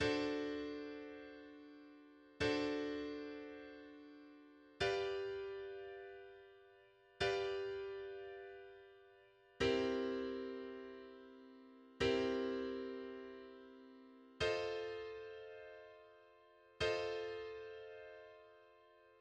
The "bridge" consists of a series of dominant seventh chords (III7–VI7–II7–V7) that follow the circle of fourths (ragtime progression), sustained for two bars each, greatly slowing the harmonic rhythm as a contrast with the A sections.